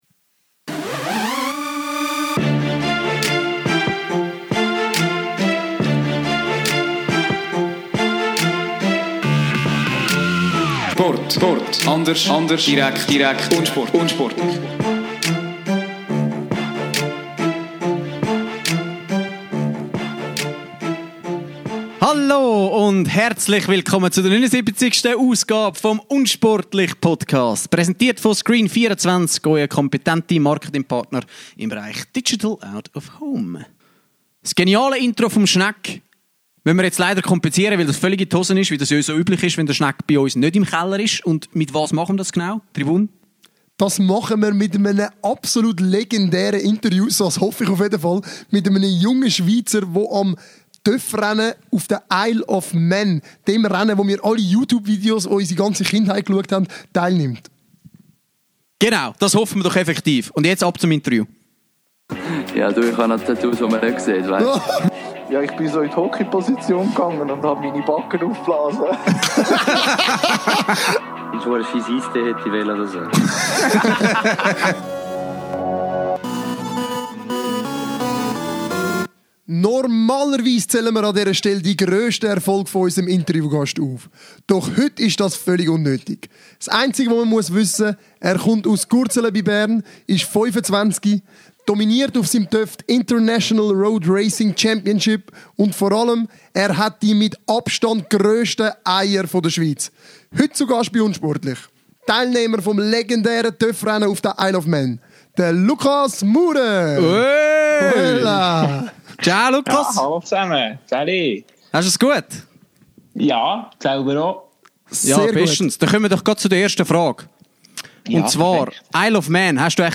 Wir sprechen mit ihm über seine Anfänge im Rennsport in einer Seifenkiste, die Angst in einem Rennen wie der Isle of Man, seine Sucht nach den Strassenrennen und darüber, was seine Familien und Freunde zu seinem Sport sagen. Ein Gespräch mit einem der spannendsten Gäste der unsportlich-Geschichte!